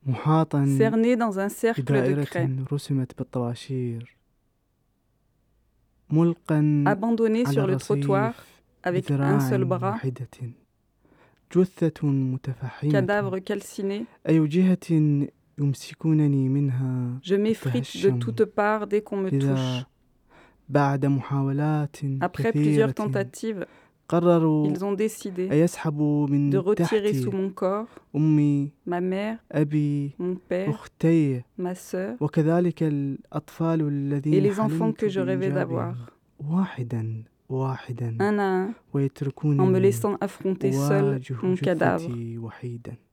Enregistrement bilingue d’un poème